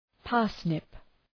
Προφορά
{‘pɑ:rsnıp}